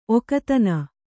♪ okatana